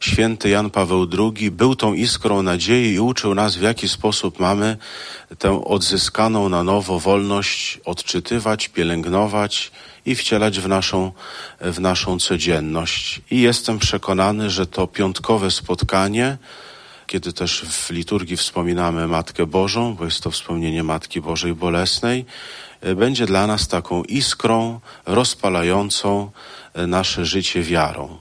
Uczestnicy dzisiejszej (13.09.) konferencji dotyczącej festiwalu 'Iskra Nadziei. Podlaskie w hołdzie Janowi Pawłowi II’ wspominali papieża Polaka.